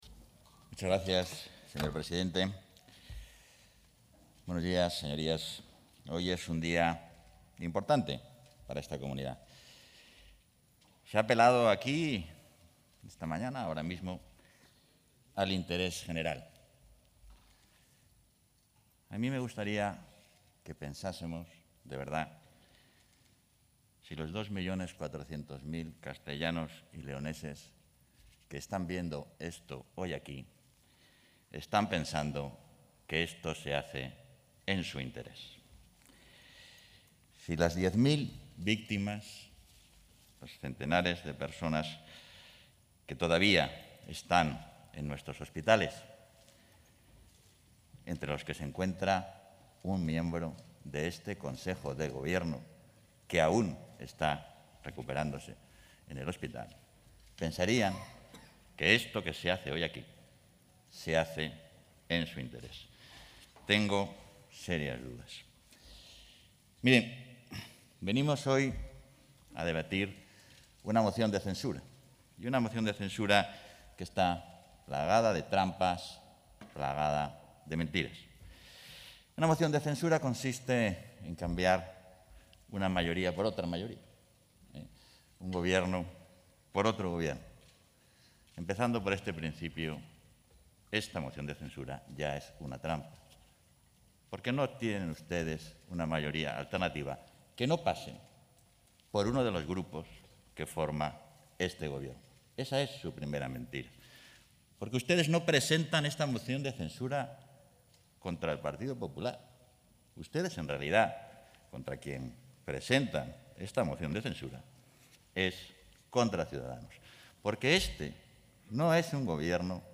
Intervención de Francisco Igea durante el pleno de Moción de Censura a la Junta de Castilla y León
Intervención del vicepresidente de la Junta.